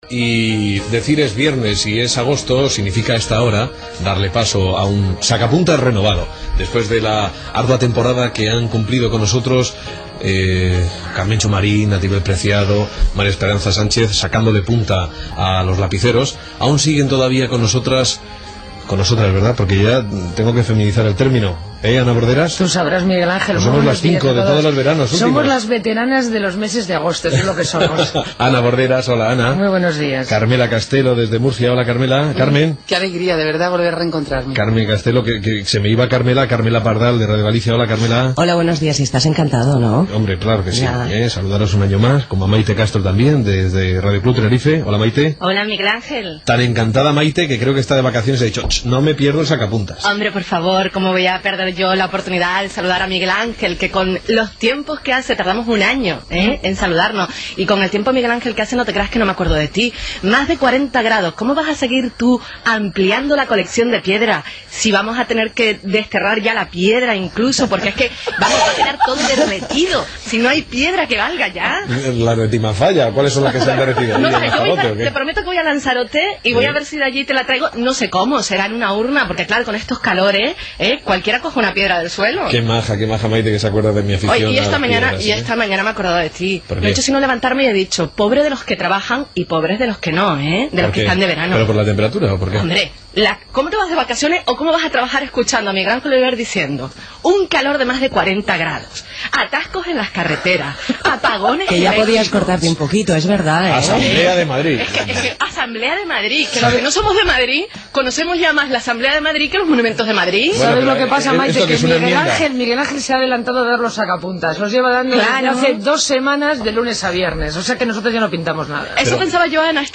Tertúlia "El sacapuntas" , Presentació de la primera tertúlia de l'estiu amb noves incorporacions i opinions sobre Green Peace, la comissió dels bancs, "las cabañuelas" a Múrcia, comportament al debat parlamentari de maltractament a les dones, els millors cotxes per mantenir relacions sexuals, etc.
Info-entreteniment